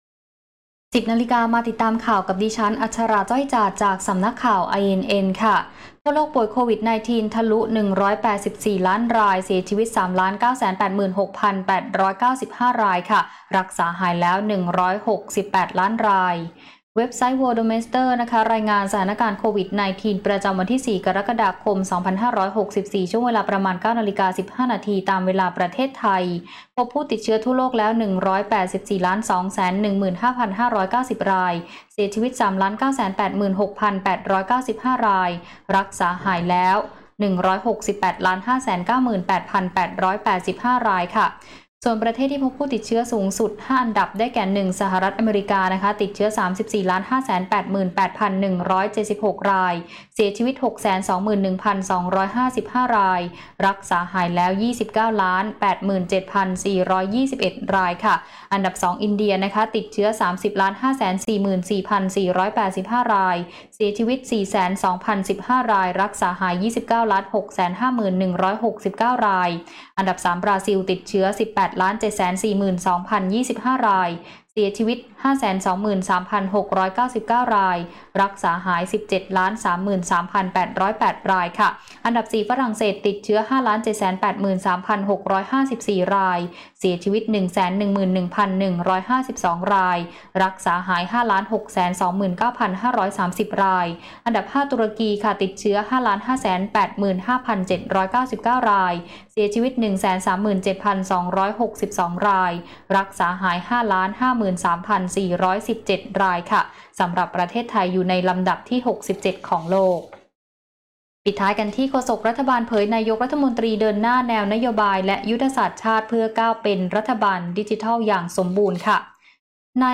คลิปข่าวต้นชั่วโมง
ข่าวต้นชั่วโมง 10.00 น.